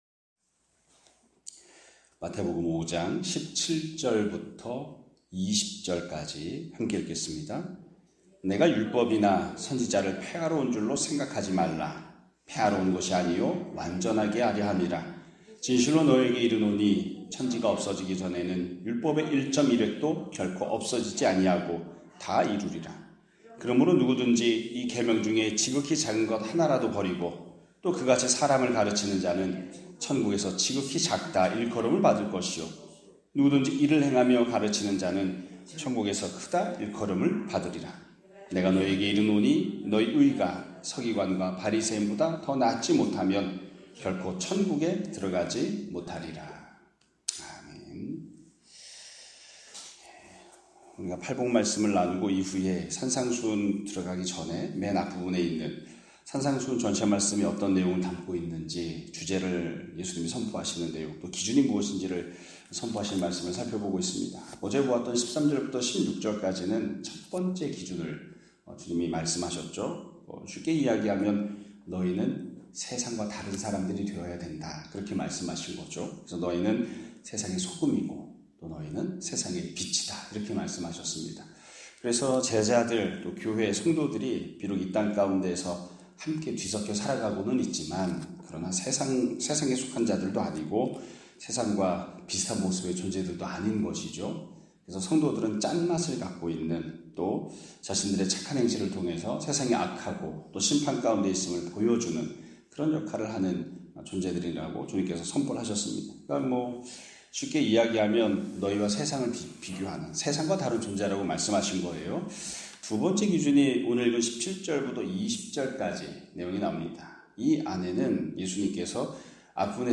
2025년 5월 21일(수요일) <아침예배> 설교입니다.